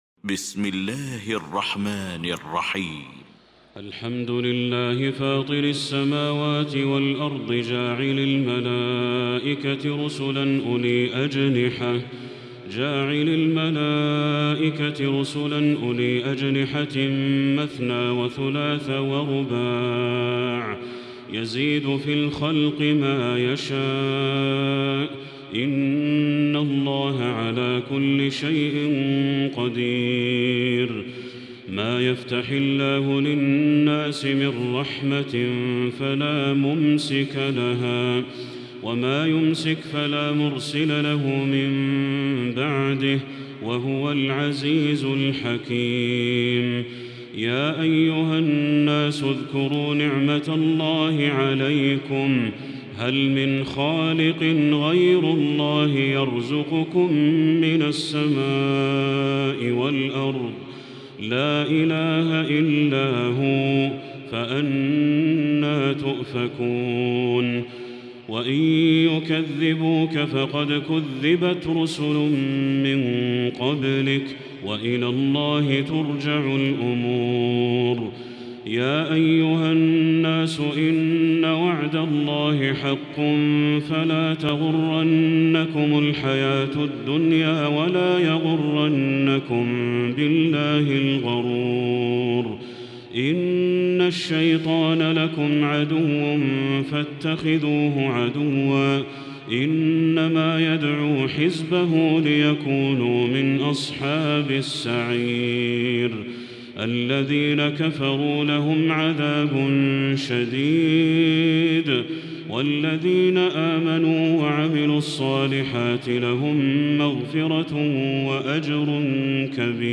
المكان: المسجد الحرام الشيخ: بدر التركي بدر التركي فاطر